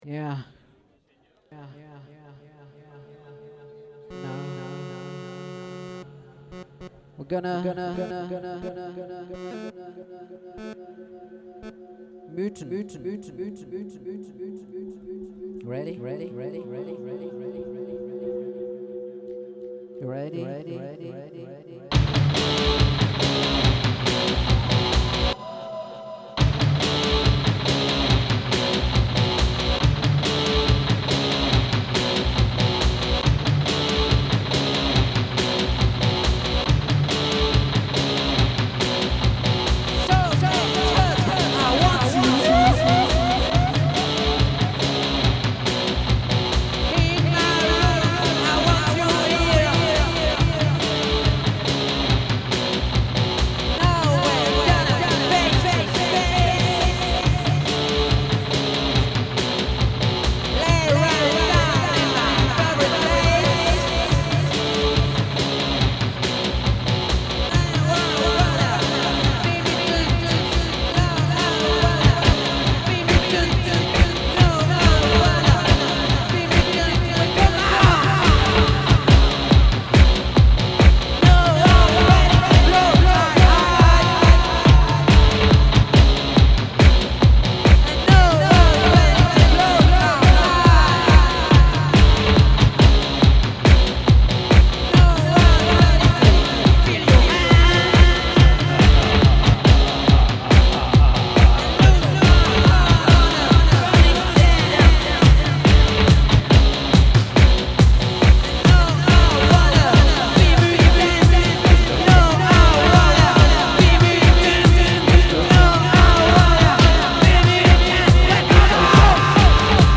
LIVE IN BLOCKAUS DY10